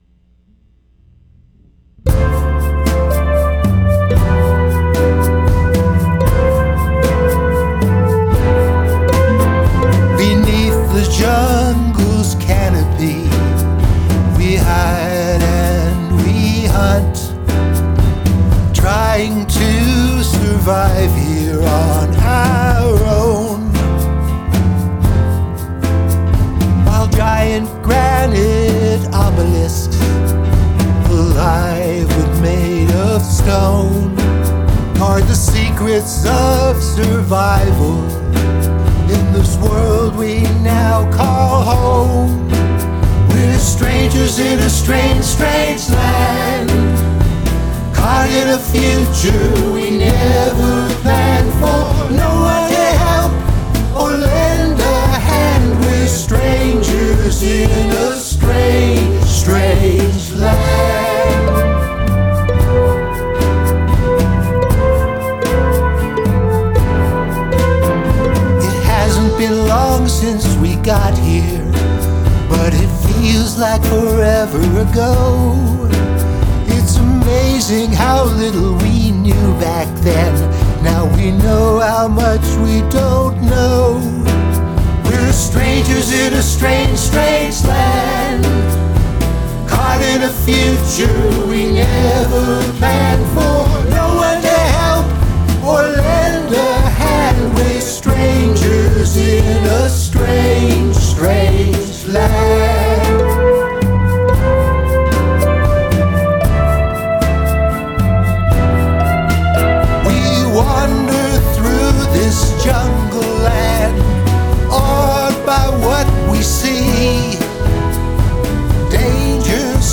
Fav is harmonies in last chorus
Very melancholy.
The drums and synth keeps it a little science fiction-y.
The melody is catchy and really interesting.
The music has a sci-fi feel to it.
It has such a vintage vibe.